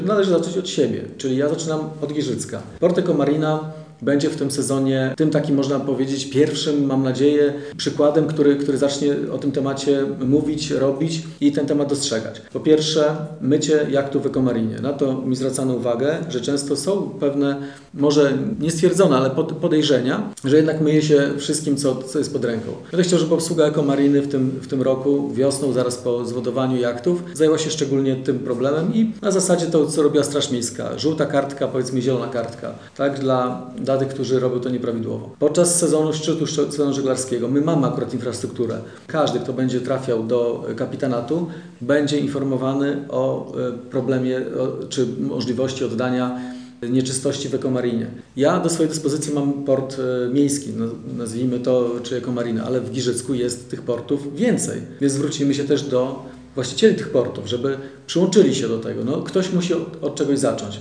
-Najbliższe już w sezonie letnim, dodaje Wojciech Iwaszkiewicz, burmistrz miasta.